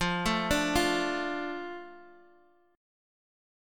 Asus4#5/F chord